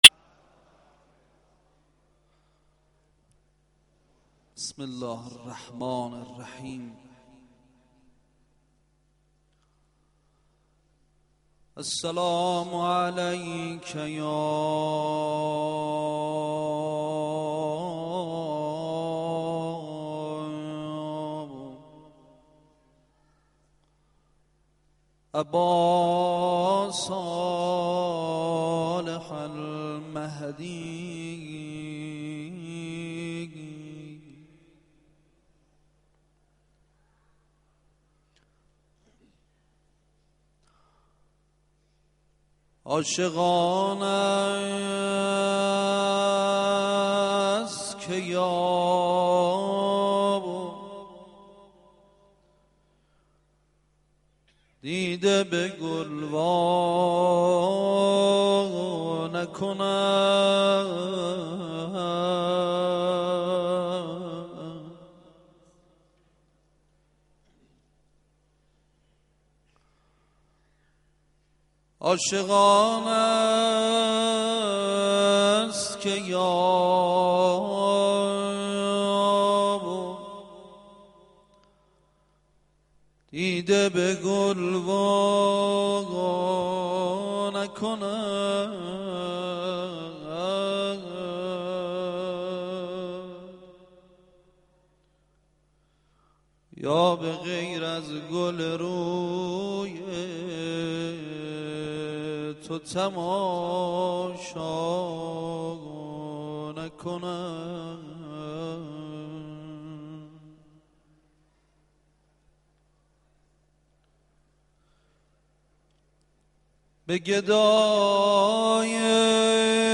مناجات امام زمان علیه السلام در مصلی حرم سیدالکریم علیه السلام95
مناجات دعای توسل